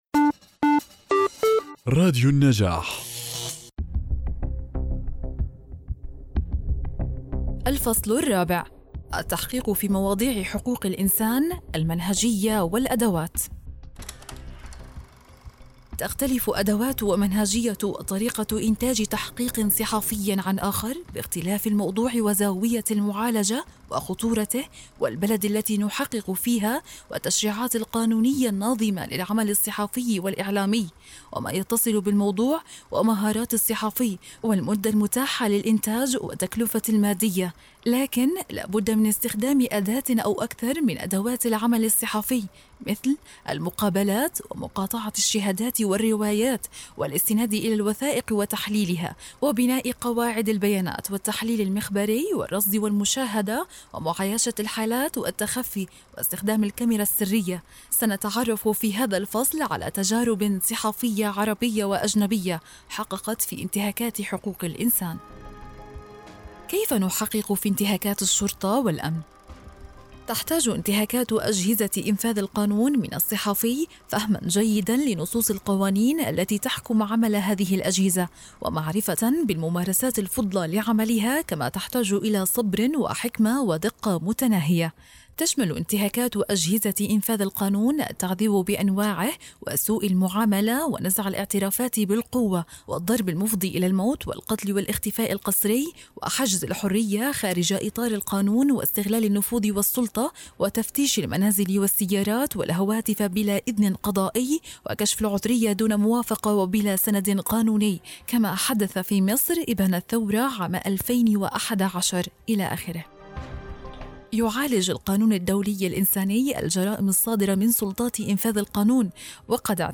الكتاب المسموع